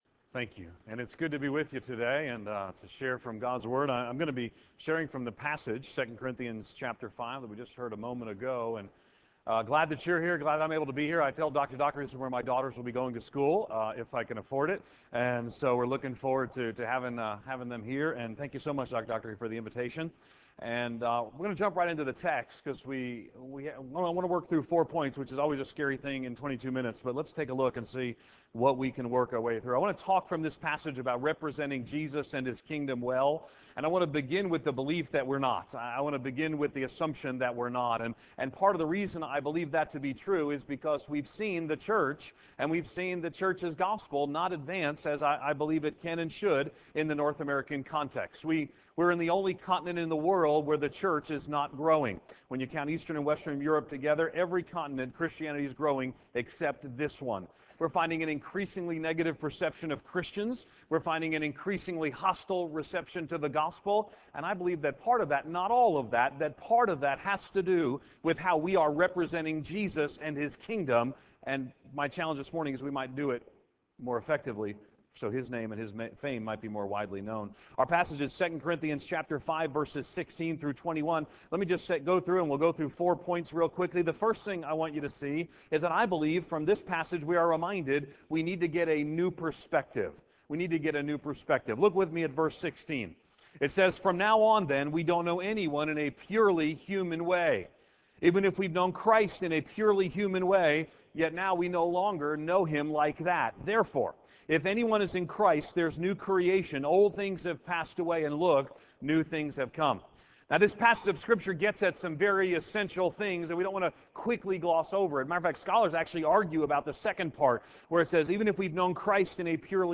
Chapel